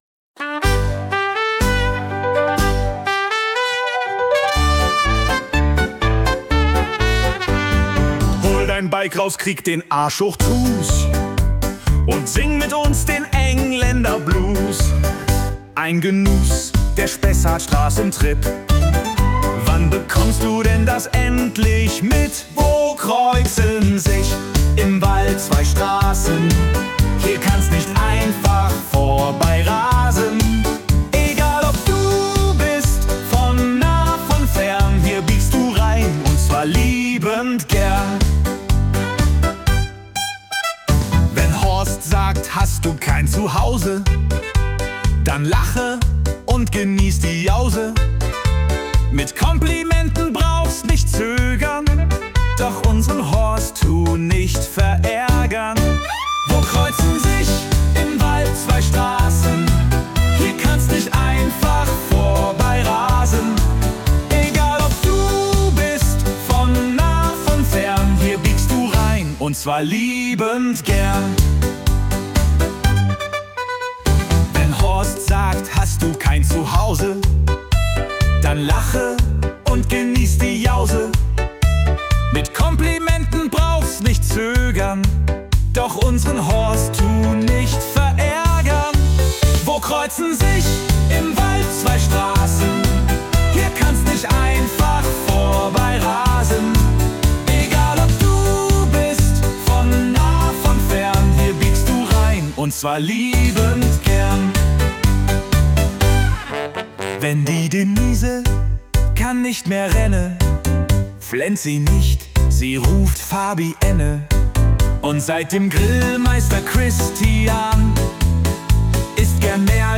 Engländer-Blues – Schlager Variante